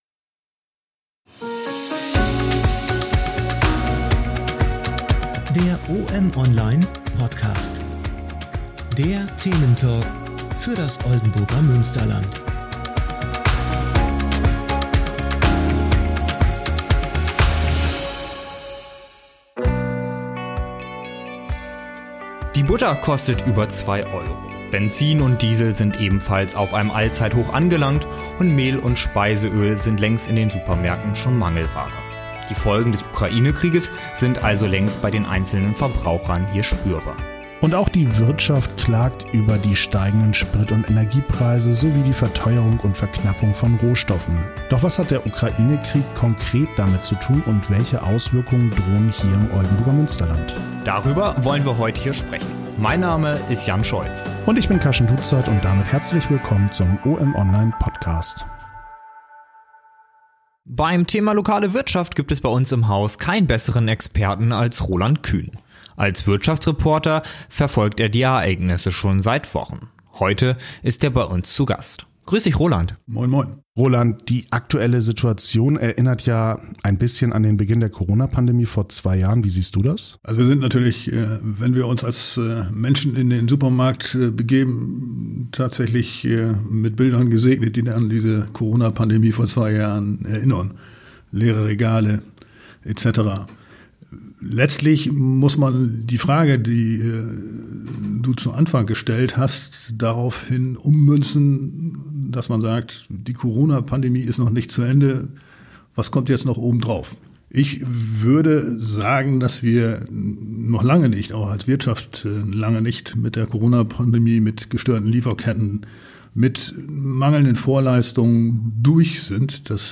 sowie weiteren Experten aus der Wirtschaft im Oldenburger Münsterland.